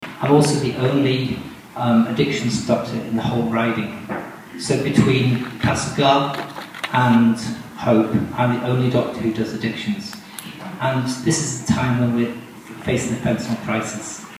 All but one provincial candidate in Boundary-Similkameen was present for this week’s forum in Grand Forks.